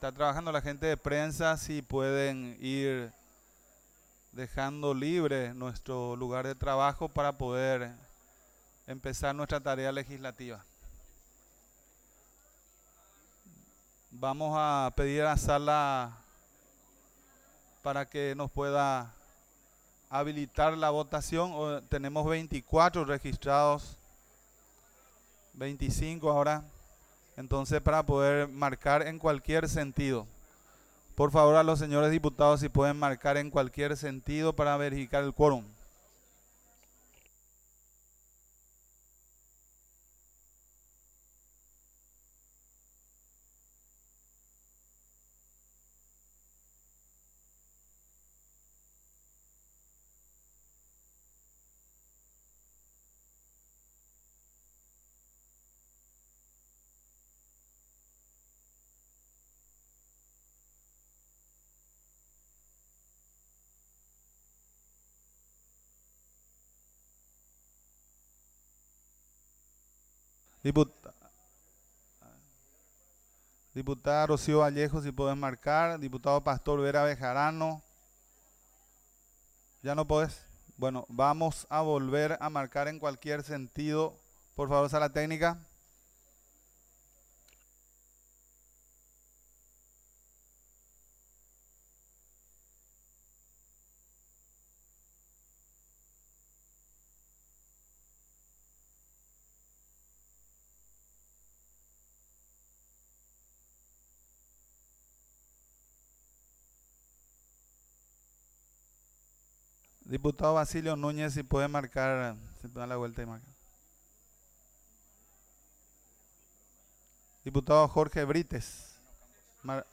Sesión Ordinaria, 7 de junio de 2023